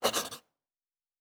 Writing 8.wav